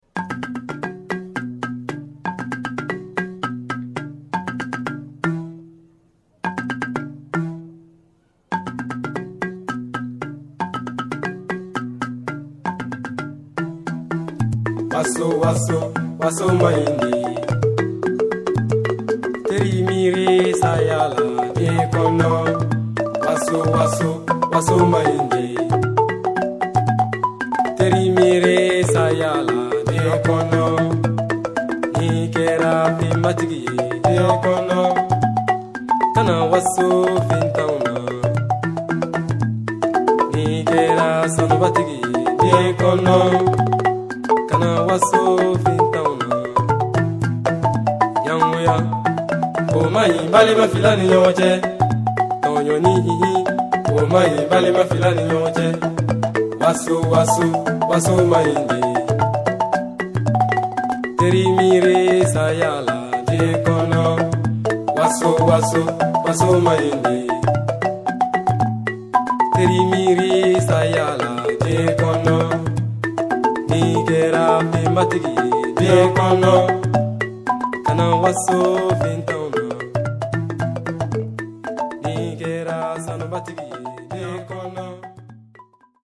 balafon_et_voix.mp3